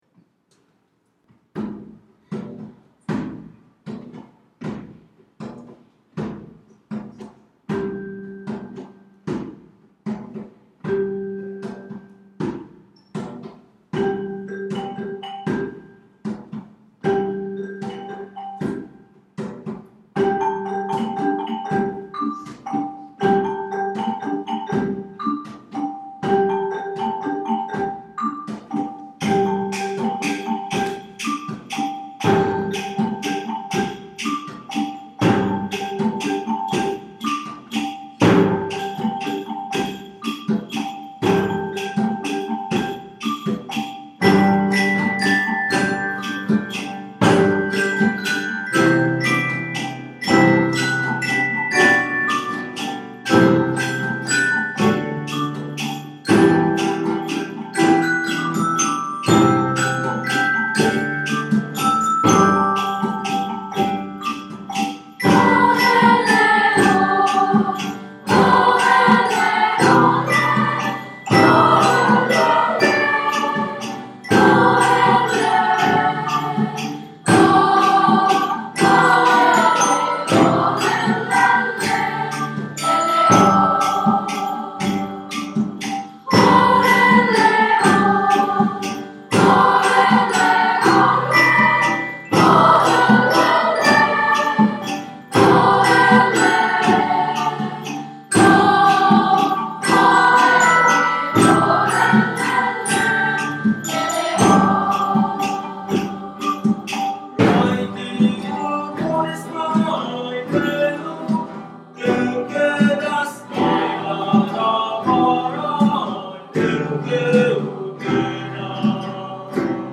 Deste encontro, nasceram dois coros, dois grupos instrumentais e duas bandas, que envolveram mais de 120 alunos.
Canção Tradicional Timorense